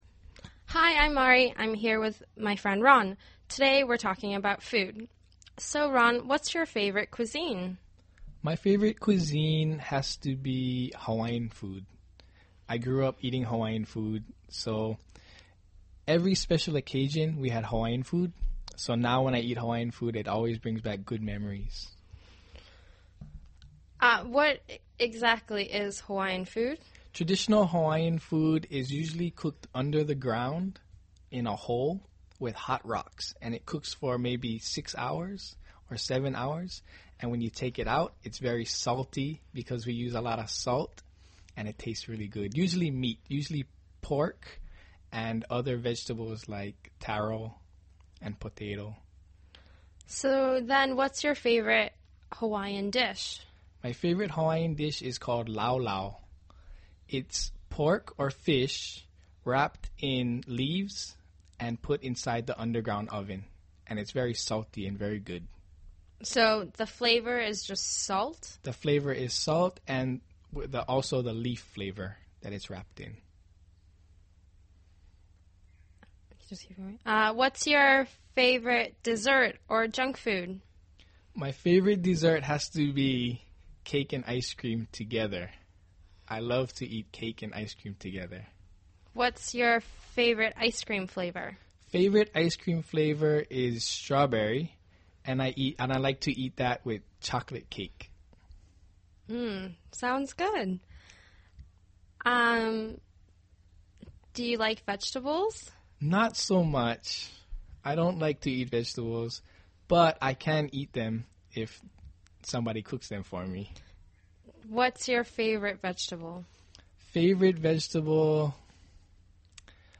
英语初级口语对话正常语速07：Ron最喜爱的食物（mp3+lrc）